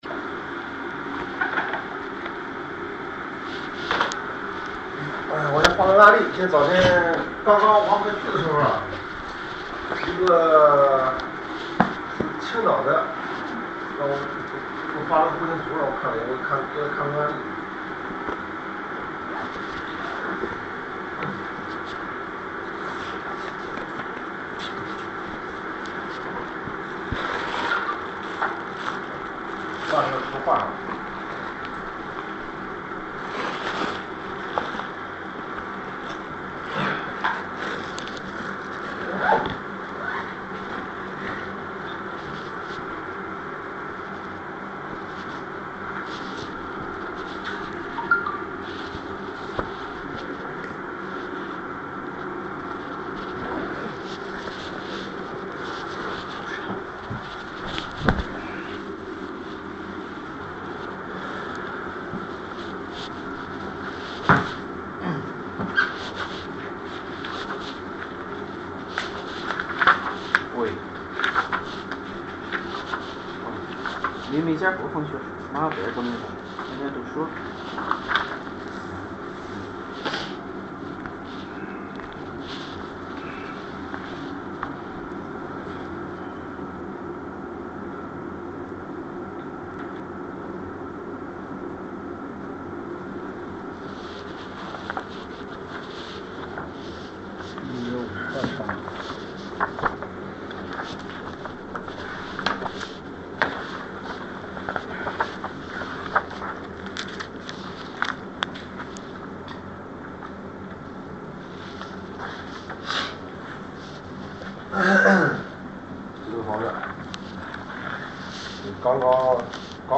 神运派风水面授录音及相关文档资料百度网盘分享下载（因为不是专门音频课程，是面授录音，所以音频不是很清晰，主要看文档，介意者慎选）